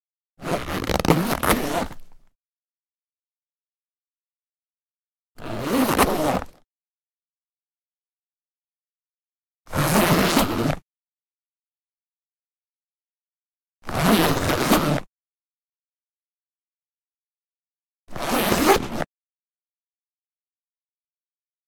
Camera Leather Case Open Close Zipper Sound
household